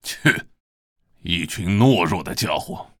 文件 文件历史 文件用途 全域文件用途 Hartz_amb_01.ogg （Ogg Vorbis声音文件，长度3.0秒，96 kbps，文件大小：35 KB） 源地址:地下城与勇士游戏语音 文件历史 点击某个日期/时间查看对应时刻的文件。